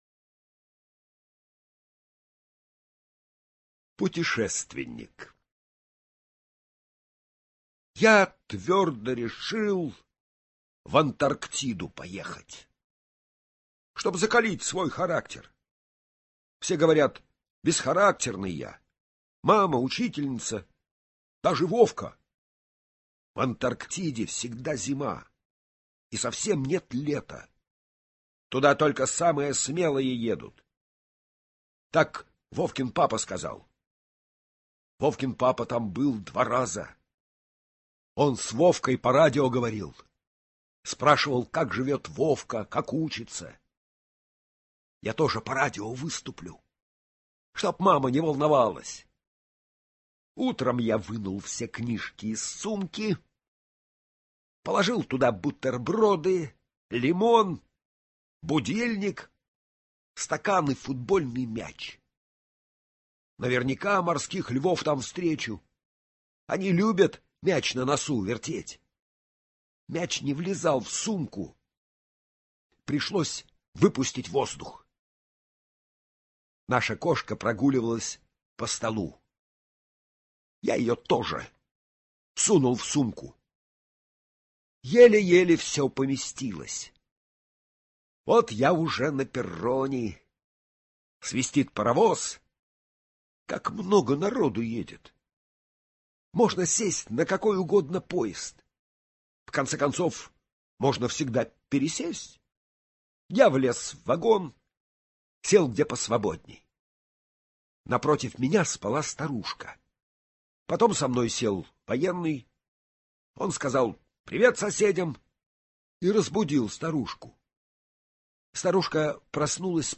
Аудиорассказ «Путешественник»